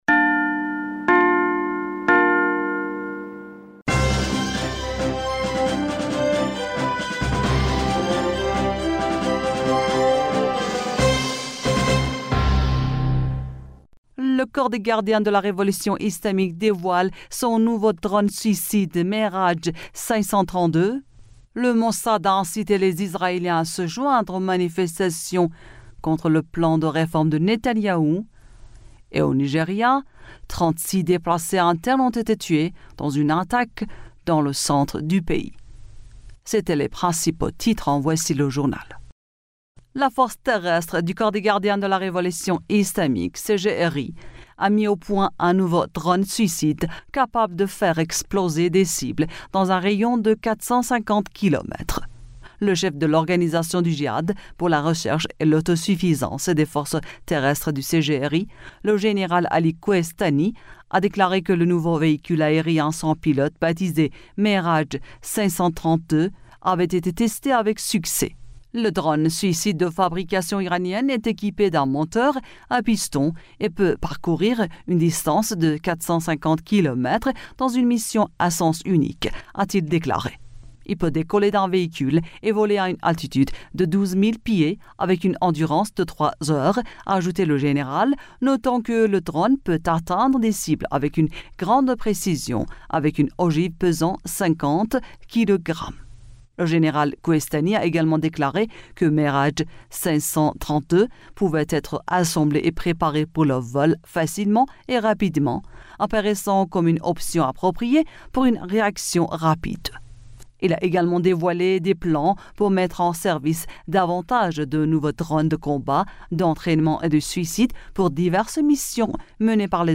Bulletin d'information du 10 Avril 2023